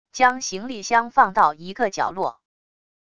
将行李箱放到一个角落wav音频